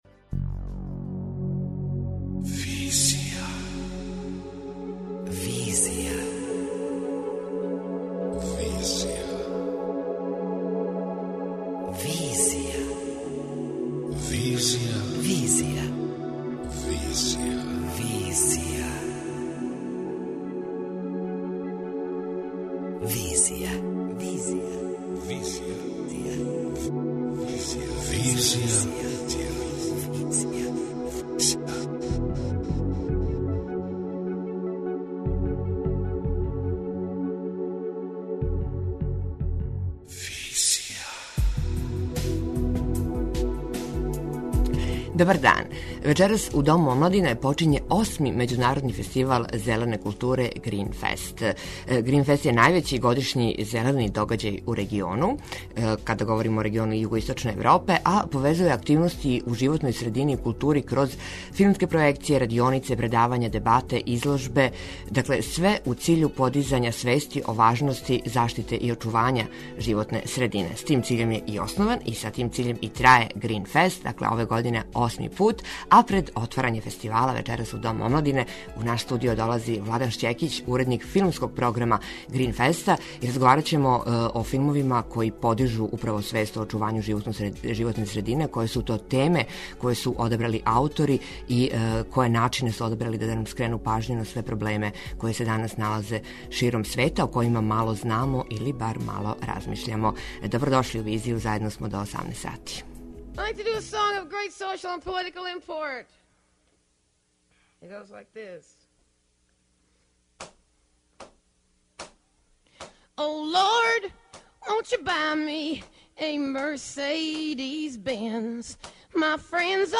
преузми : 26.52 MB Визија Autor: Београд 202 Социо-културолошки магазин, који прати савремене друштвене феномене.